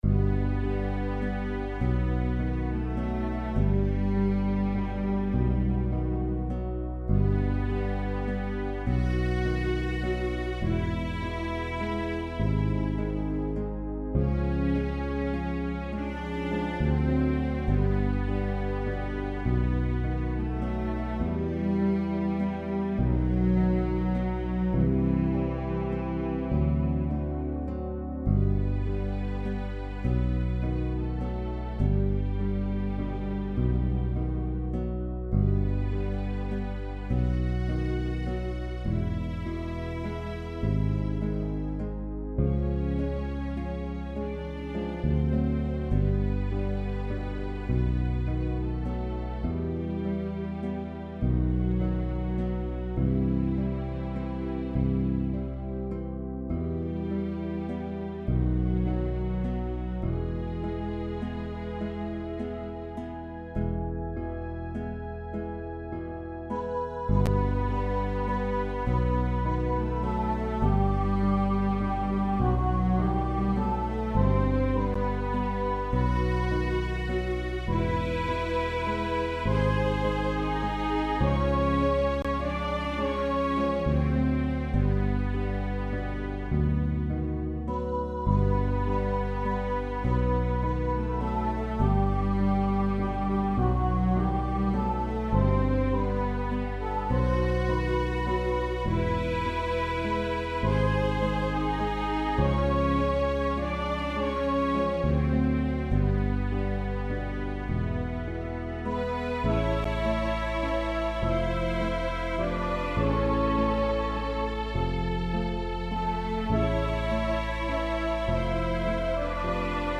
My Aunt Sarah Falls (Sept 28) 5:38 6) Aunt Sarah (ten_bass) 5:38 6) Aunt Sarah (sop_alt) 8:45 6) Aunt Sarah (tutti) 8:45 6) Aunt Sarah (Tenor) 8:45 6) Aunt Sarah (Sop) 8:45 6) Aunt Sarah (Bass) 8:45 6) Aunt Sarah (Alto)